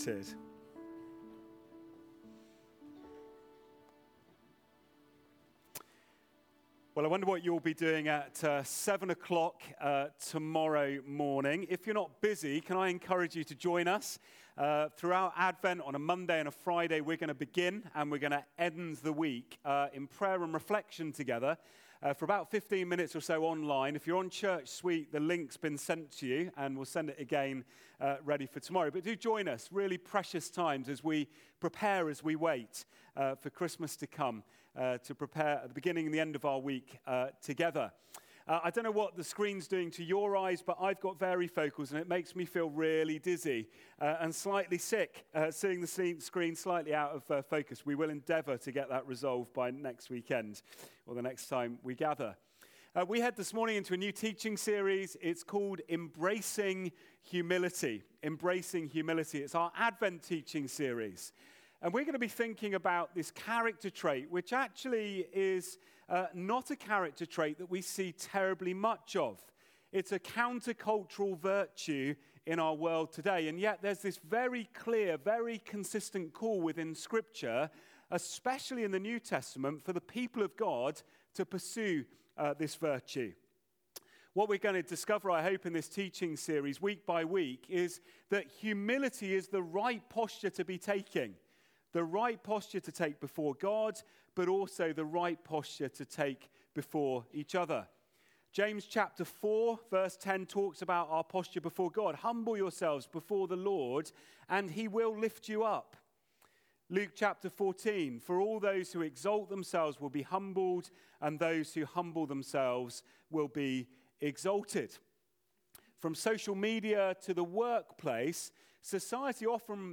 Sermons From Christchurch Baptist Church (CBCDorset)